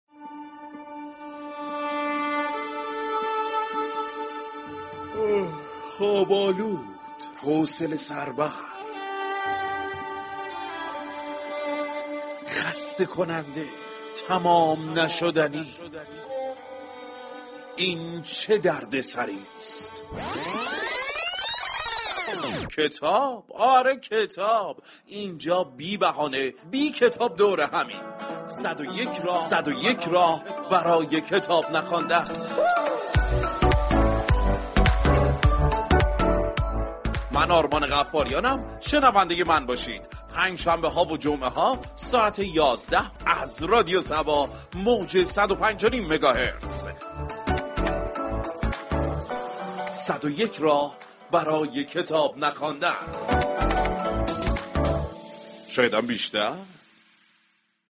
«صدویك راه برای كتاب نخواندن» درهر قسمت به روش های گوناگون كتاب هایی متنوع در حوزه های روانشناسی ، داستان خوانی و تاریخی به مخاطبان معرفی می كند، دراین برنامه گاهی با گفتگو كارشناسی یك كتاب معرفی می شود و گاهی با پخش سكانس هایی از فیلمی كه از روی كتاب ساخته شده آن را به شنوندگان معرفی می كنند.